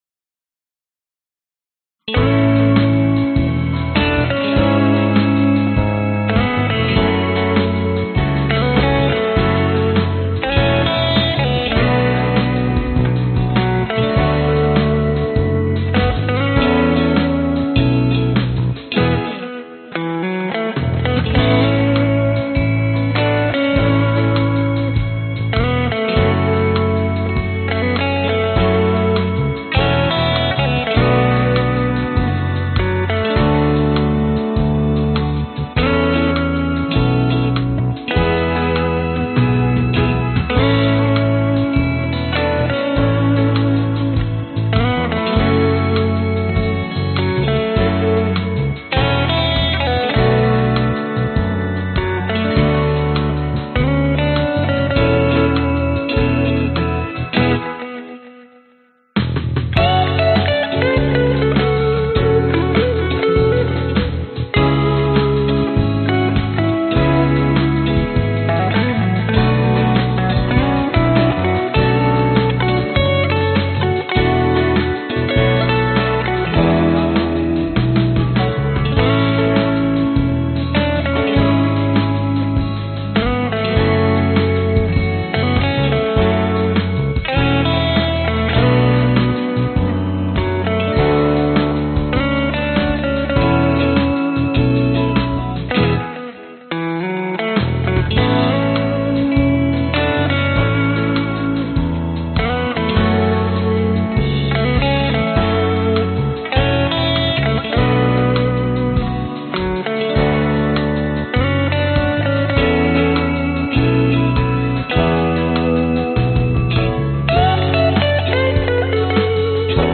Tag: 吉他 贝斯 钢琴 风琴